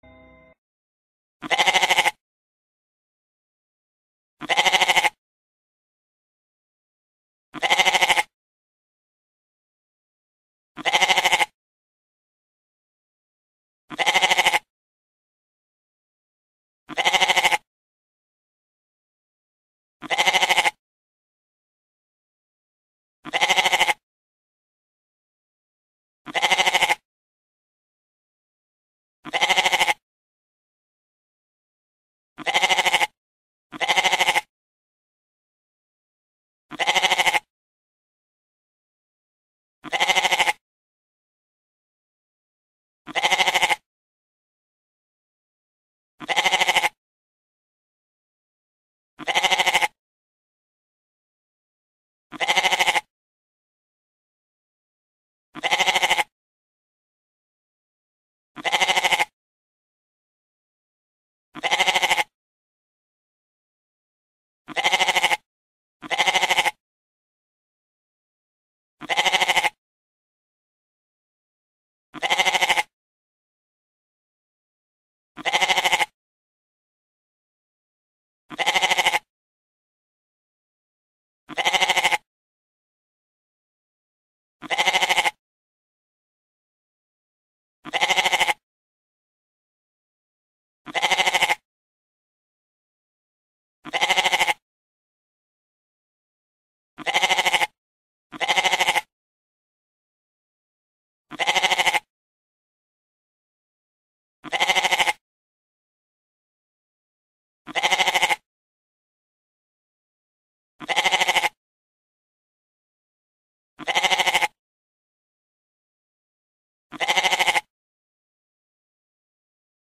Tiếng Dê kêu
Tiếng động vật 332 lượt xem 10/03/2026
Download tiếng Dê kêu mp3, tải tiếng con Dê kêu be be be mp3.